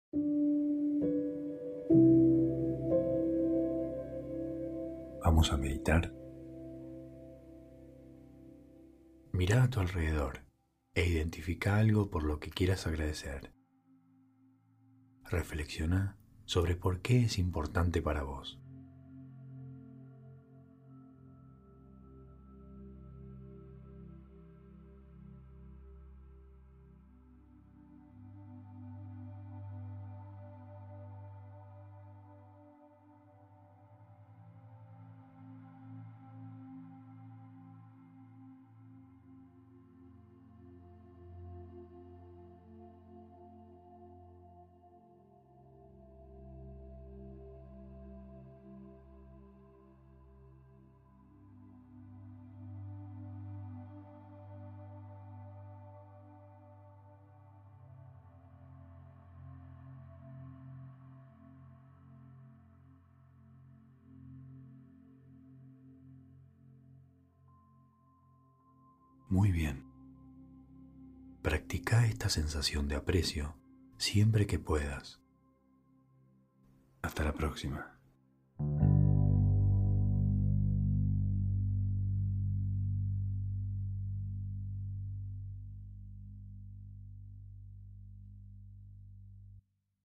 Meditación de 1 minuto para reflexionar.